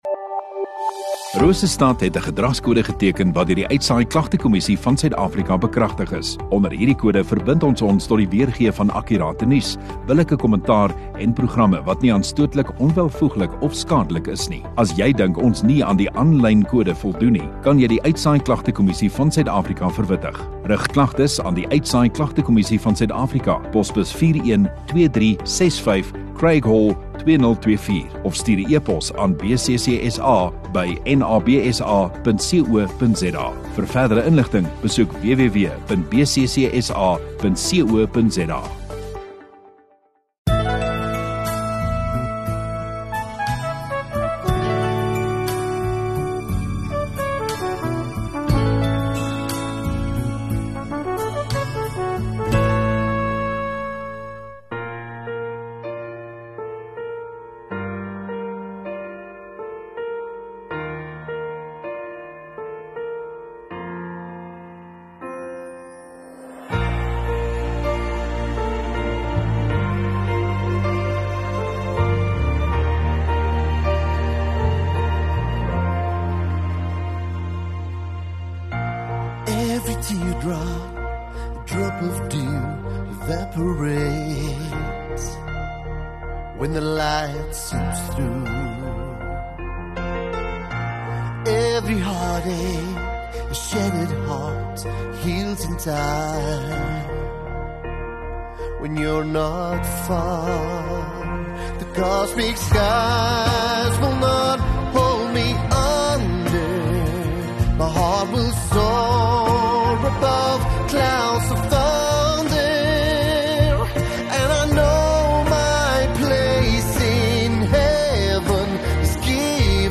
11 Aug Sondagoggend Erediens